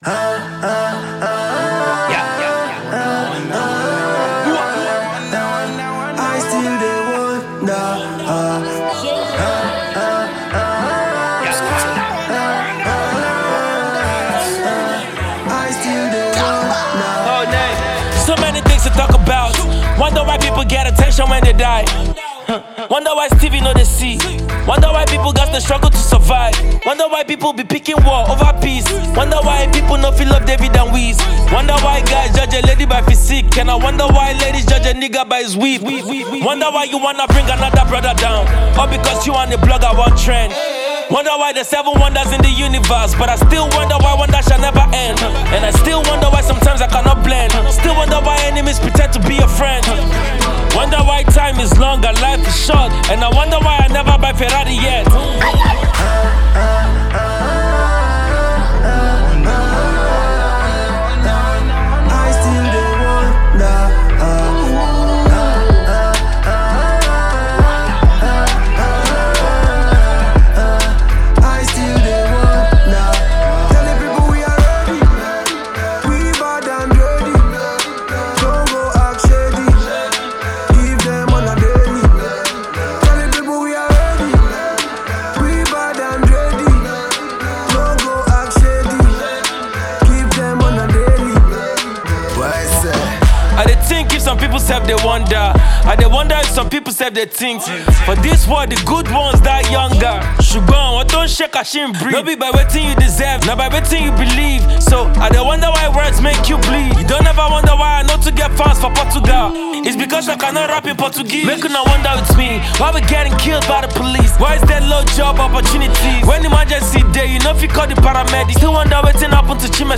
dancehall singer